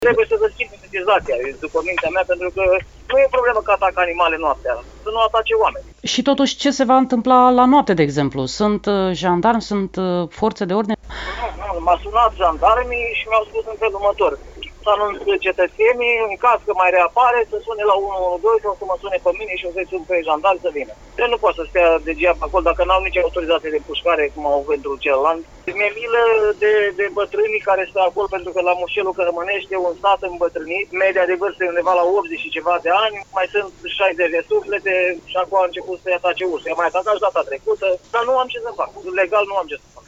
Primar-Colti-2.mp3